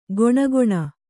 ♪ goṇagoṇa